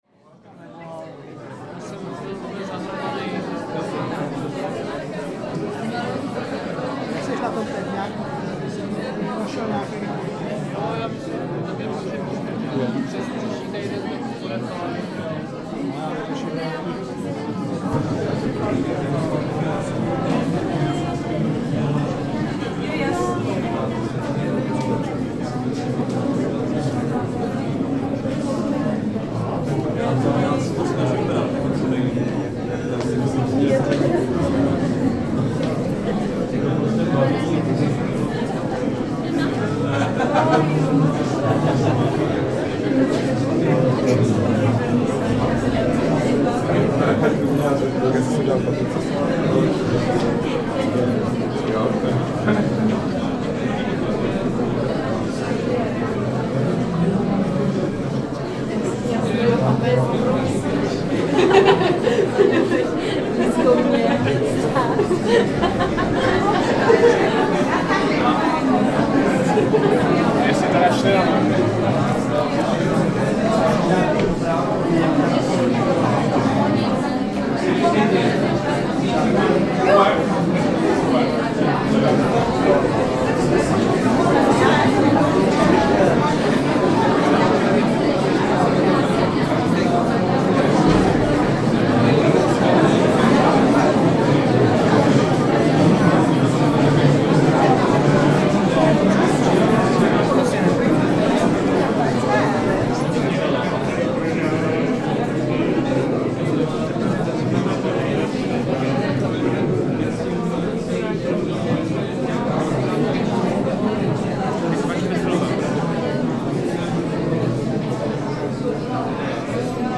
vernissage of Vladimir Kokolia exhibition in Spalova gallery.